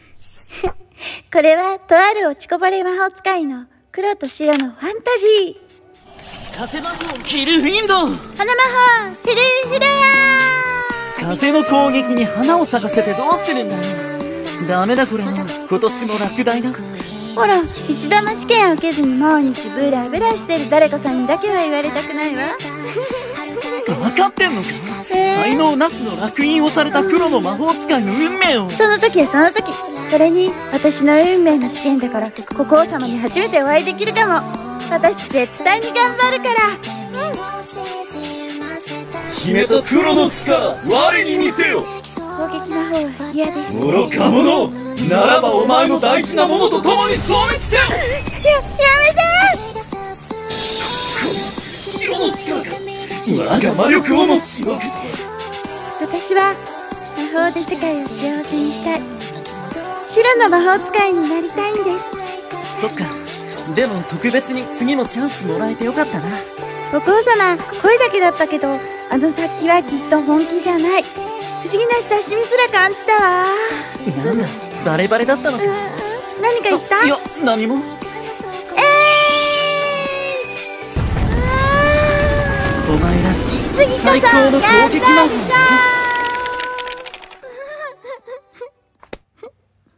】2人声劇『Tales of Whitches 』 メル/。